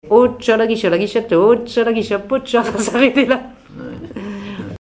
Genre brève
Conversation sur les musiciens de Saint-Pierre et Miquelon
Pièce musicale inédite